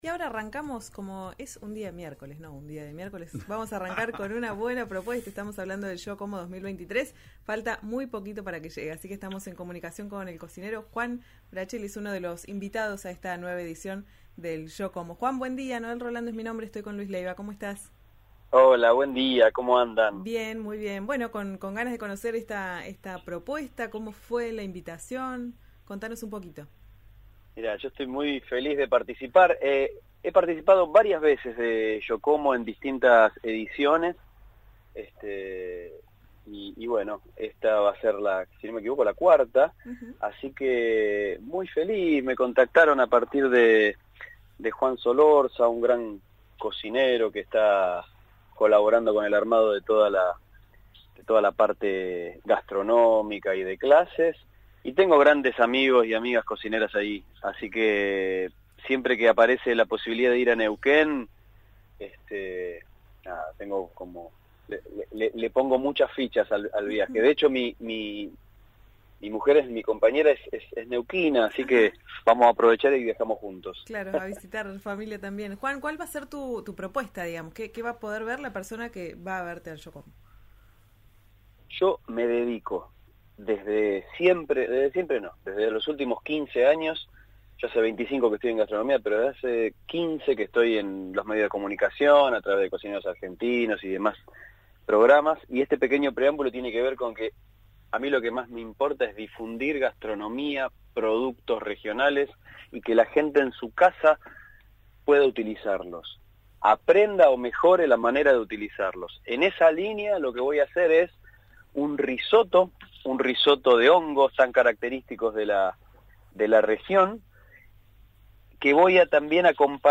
Escuchá a Juan Braceli en RÍO NEGRO RADIO: